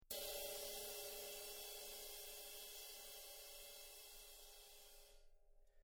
sizzle-cymbal__long_mezzo-piano_sticks.mp3